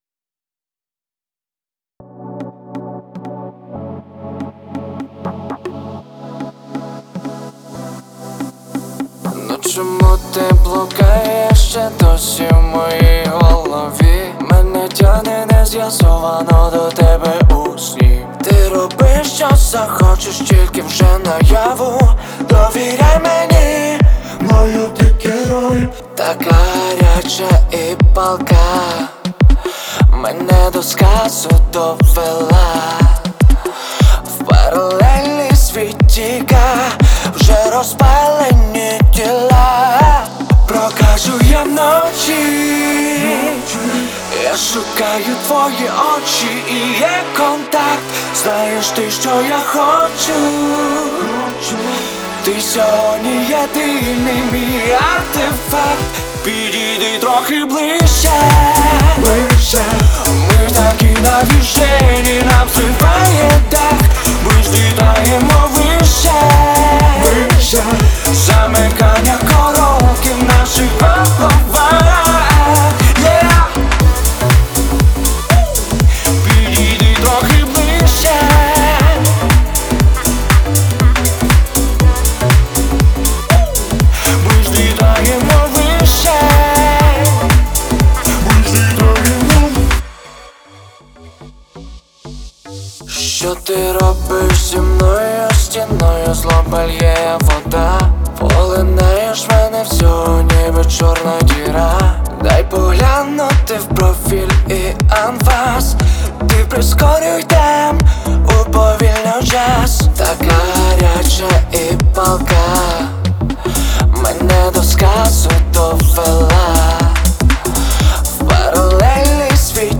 • Жанр: Русские песни